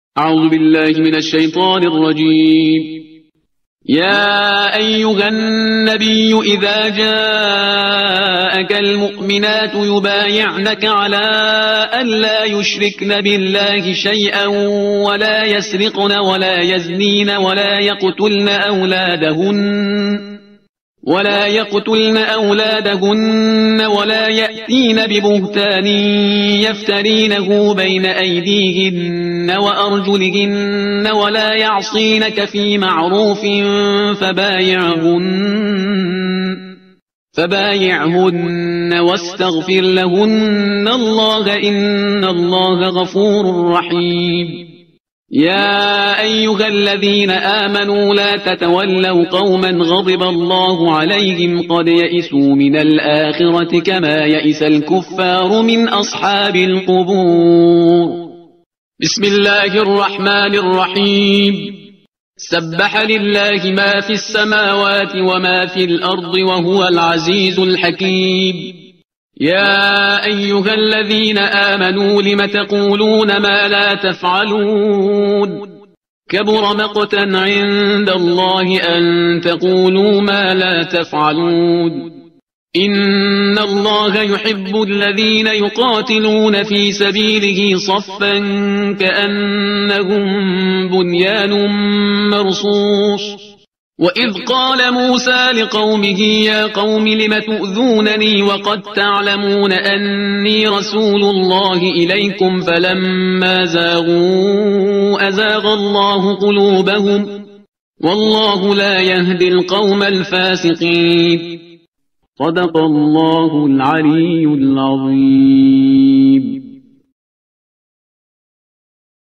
ترتیل صفحه 551 قرآن با صدای شهریار پرهیزگار